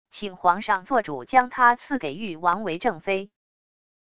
请皇上做主将她赐给御王为正妃wav音频生成系统WAV Audio Player